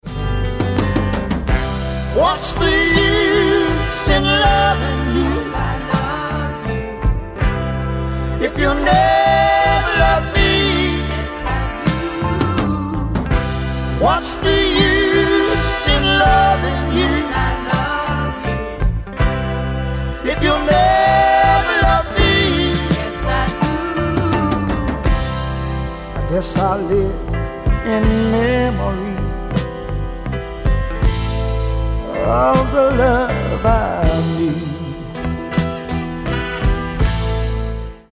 ギター、ヴォーカル
オルガン、ヴォーカル、フロント・マン
ドラムス